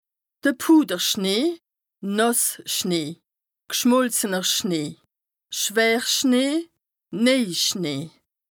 Bas Rhin
Ville Prononciation 67
Strasbourg